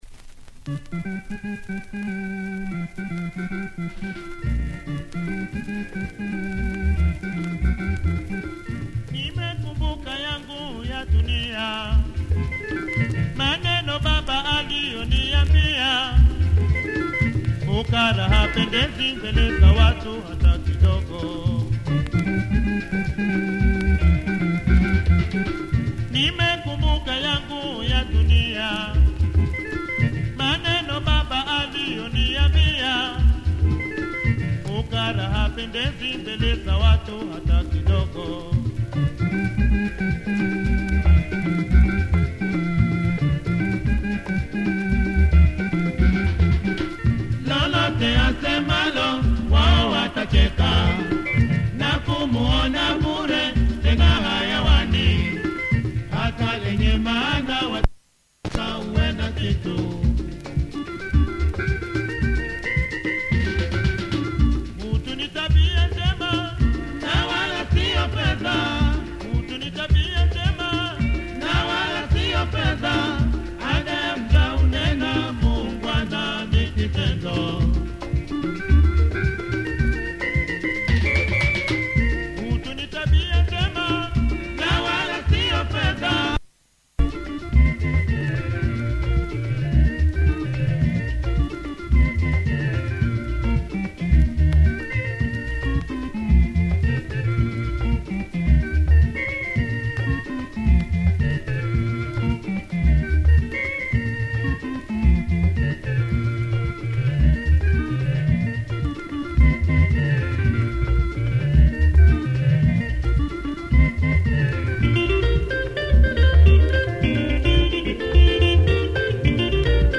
Nice organ
large orchestra and guitar